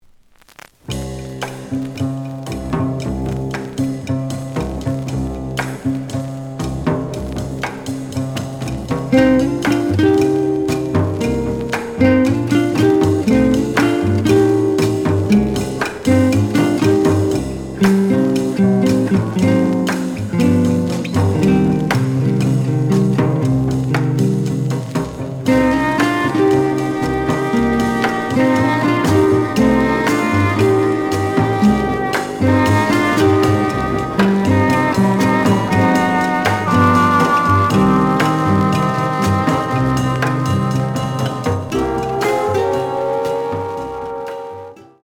The audio sample is recorded from the actual item.
●Genre: Modern Jazz
Looks good, but slight noise on both sides.)